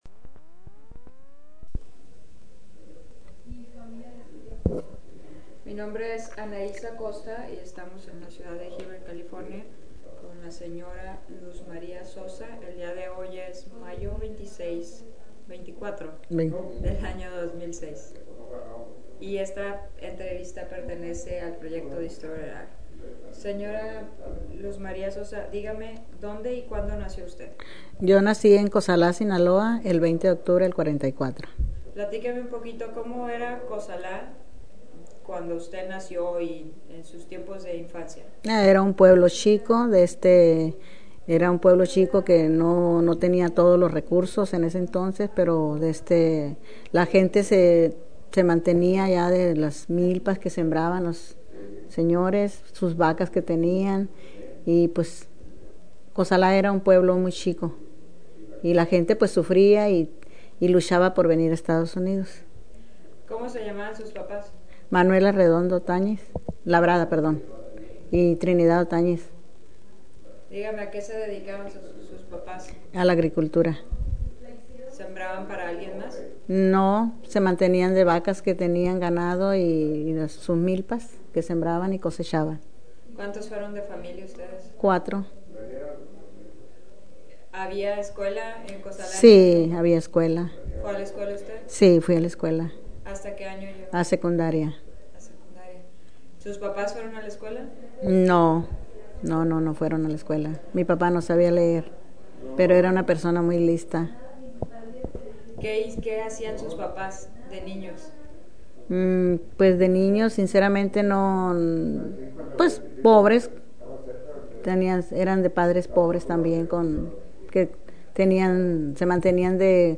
Original Format Mini disc
Location Heber, CA